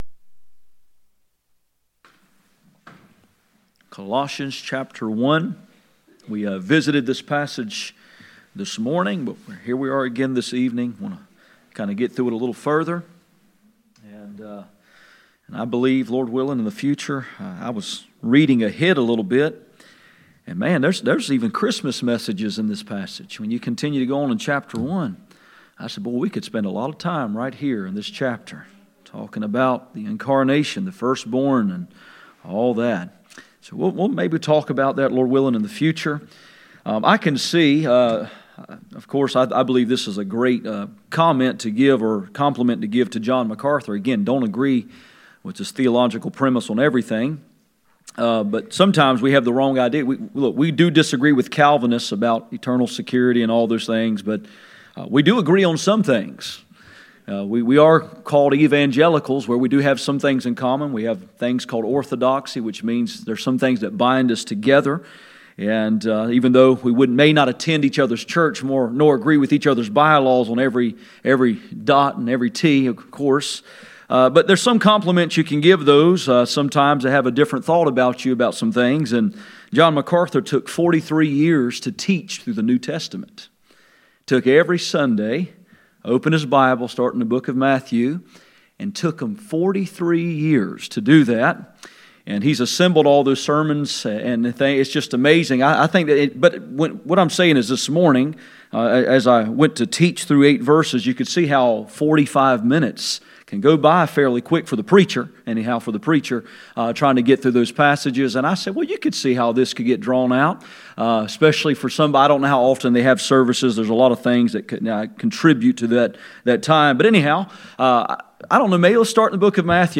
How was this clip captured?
Colossians 1:1-7 Service Type: Sunday Evening %todo_render% « The preeminence of Christ